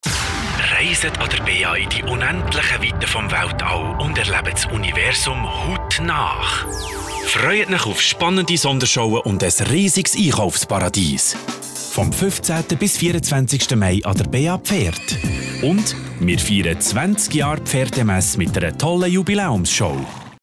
Werbung Schweizerdeutsch (BE)
Meistgebuchter Sprecher mit breitem Einsatzspektrum. Diverse Dialekte und Trickstimmen.